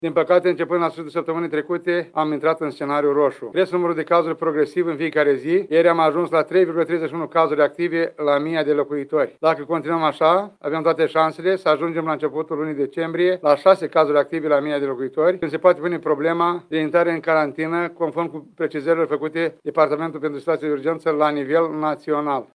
Orașul Suceava ar putea intra din nou în carantină la începutul lunii decembrie, dacă numărul cazurilor nu o să scadă – o spune chiar primarul Ion Lungu.